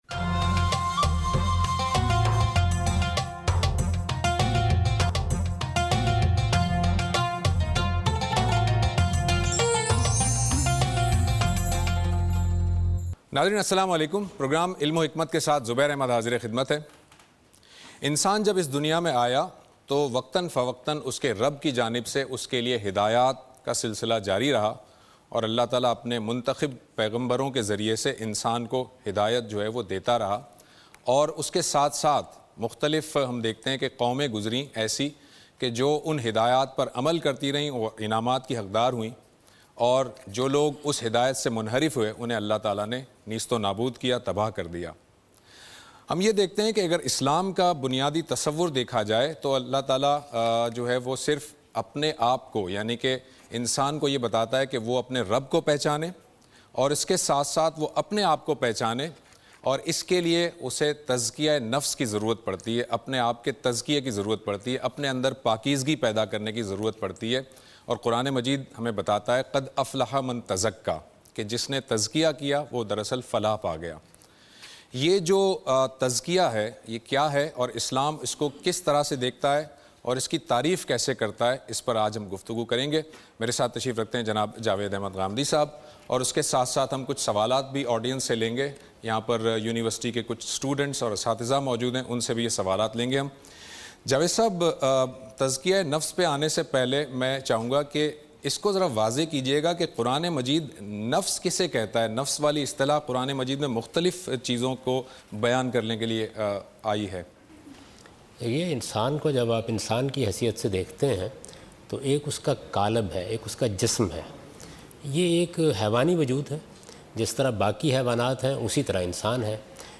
Javed Ahmad Ghamidi answers the questions about "Purification" in program "Ilm-o-Hikmat" on Dunya News.